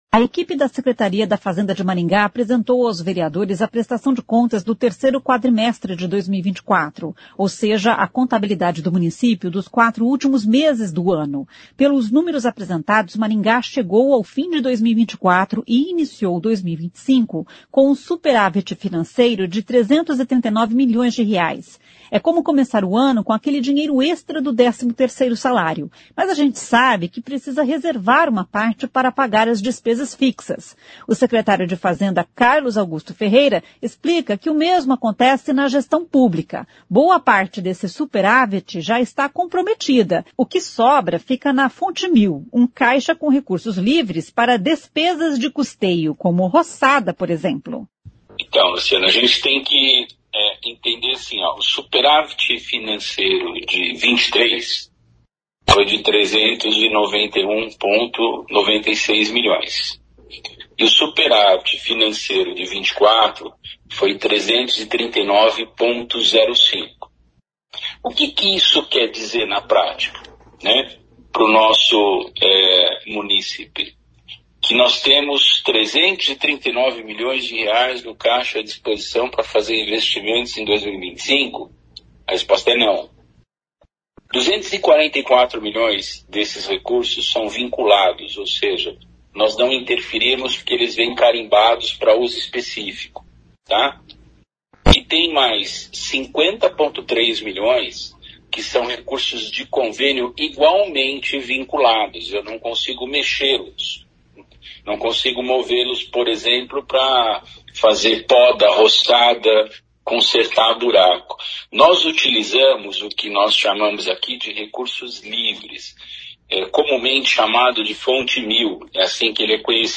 O secretário de Fazenda Carlos Augusto Ferreira explica que o mesmo acontece na gestão pública.
Na Câmara Municipal, o presidente interino Sidnei Telles, integrante da Comissão de Finanças e Orçamento (CFO), diz que esta prestação de contas é muito relevante por ser a última da gestão anterior. [ouça o áudio]